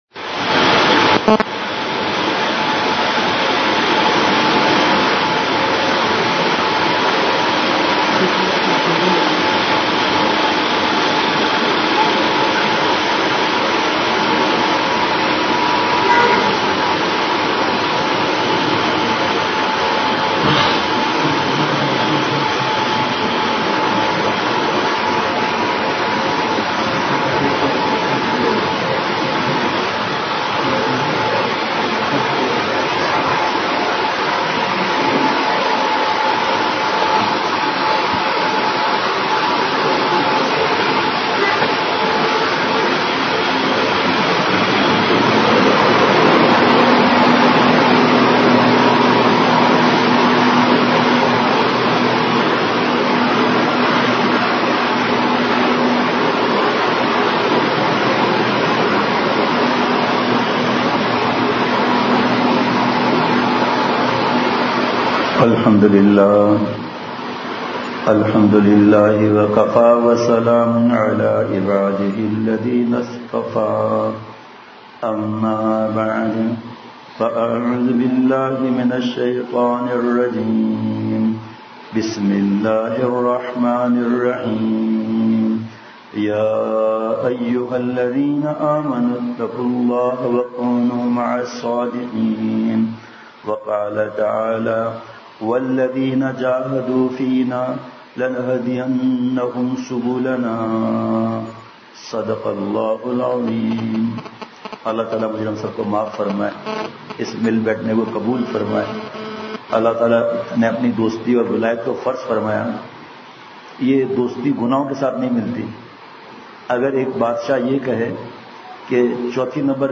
بمقام: مدرسہ امام ابوحنیفہ پشین